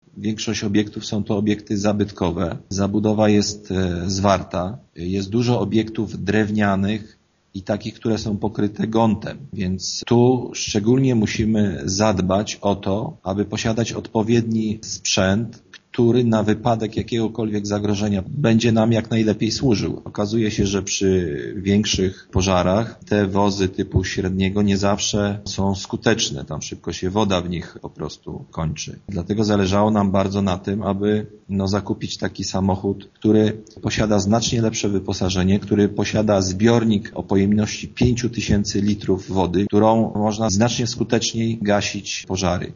Burmistrz Dunia tłumaczy, że ze względu na specyfikę miasteczka, taki sprzęt jest miejscowej OSP bardziej potrzebny: